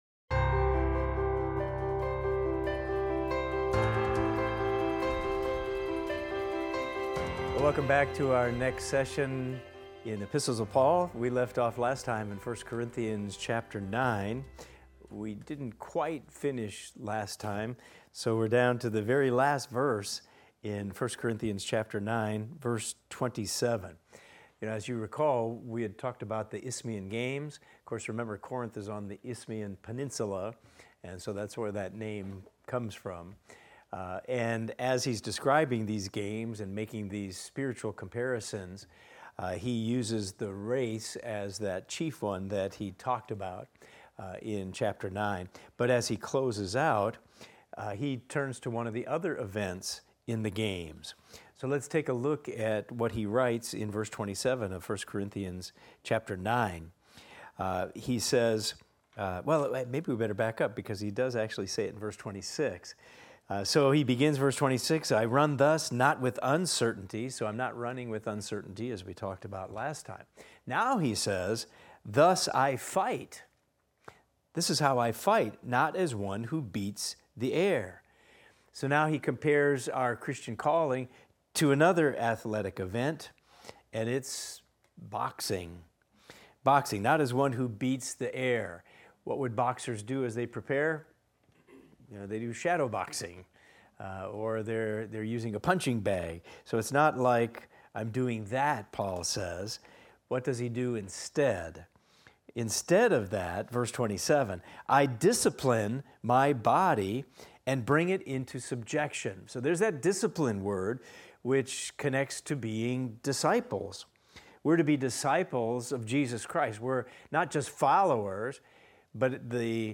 In this class we will discuss 1 Corinthians 9:27 thru 1 Corinthians 10:13 and examine the following: Paul stresses self-discipline to avoid disqualification despite preaching to others.